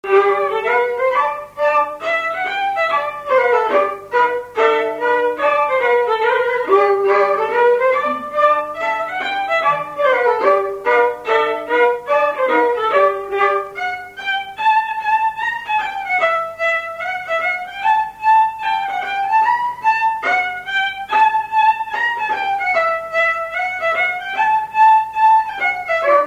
Scottish
Résumé instrumental Usage d'après l'analyste gestuel : danse
Pièce musicale inédite